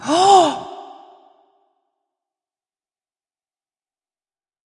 人声 " 震惊
描述：女声，震惊，惊喜，惊恐
Tag: 语音 惊喜 休克 恐惧 人类